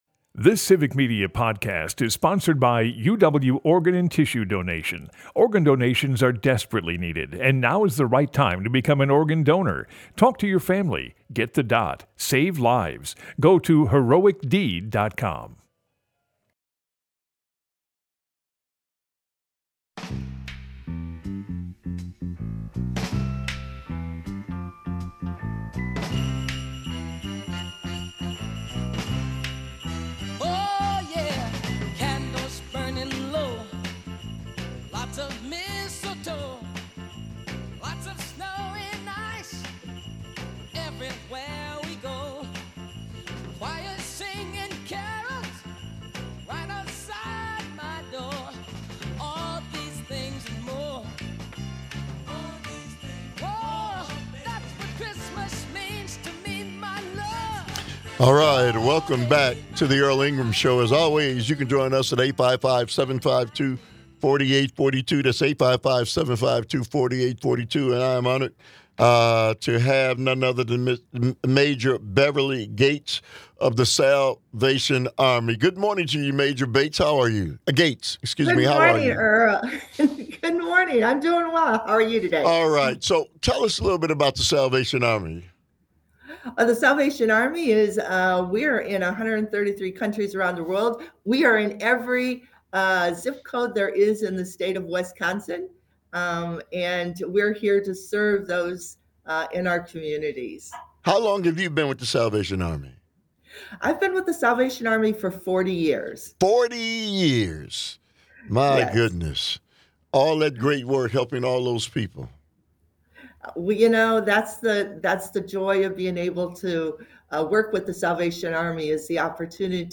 Broadcasts live 8 - 10am weekdays across Wisconsin.